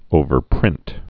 (ōvər-prĭnt)